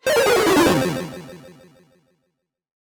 GameOver.wav